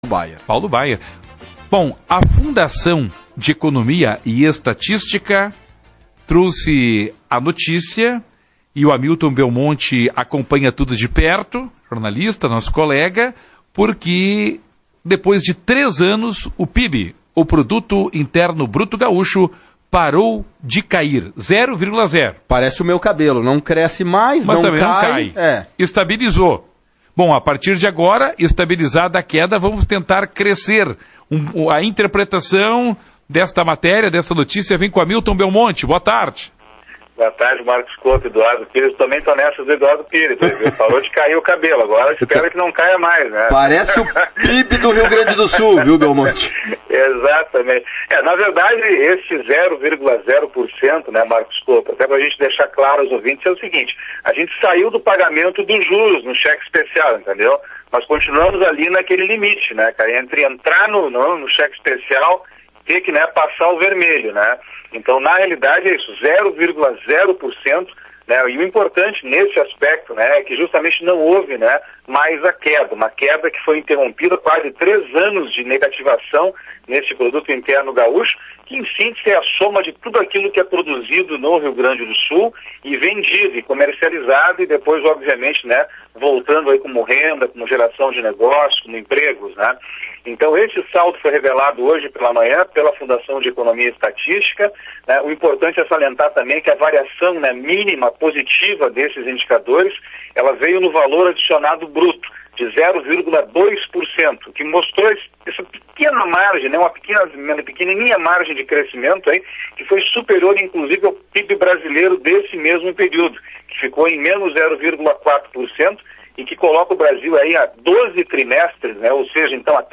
(transcri��o autom�tica, sujeita a erros) Assembleia discute proposta de medida constitucional; Apresentadores criticam a poss�vel extin��o da FEE.